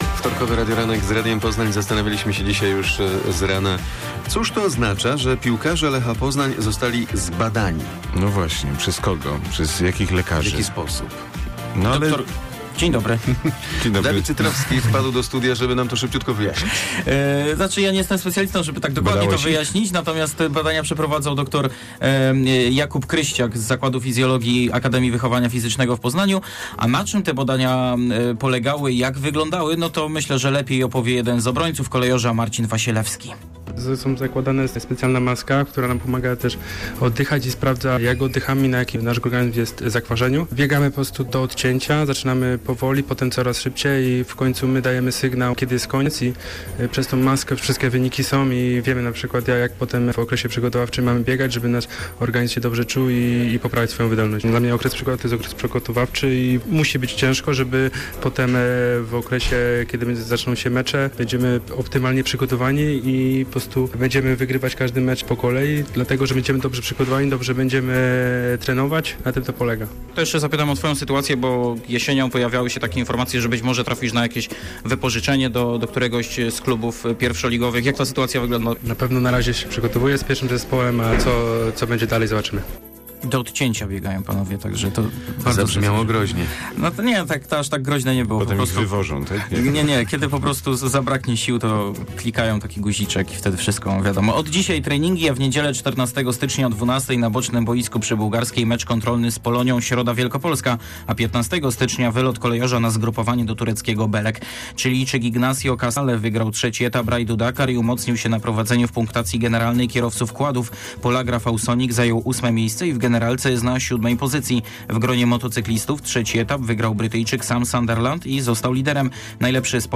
09.01 serwis sportowy godz. 7:45